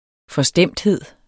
Udtale [ fʌˈsdεmˀdˌheðˀ ]